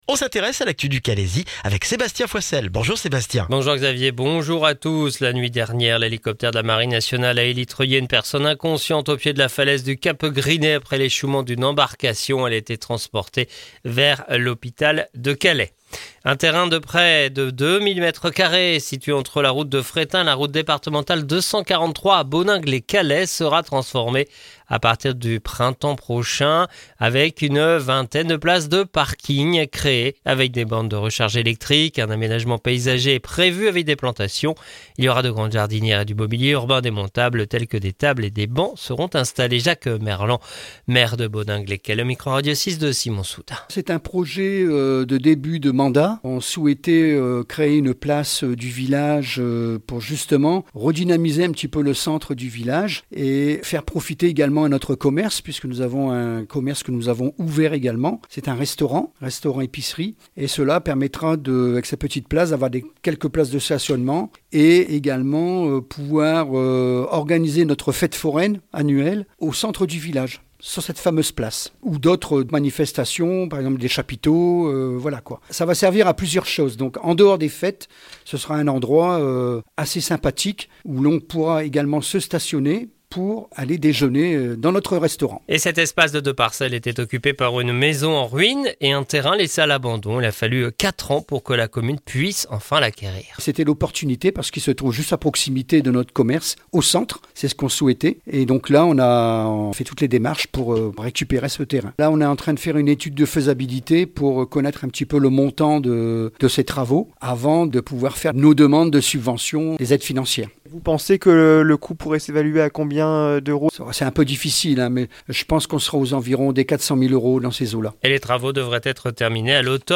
Le journal du vendredi 8 novembre dans le Calaisis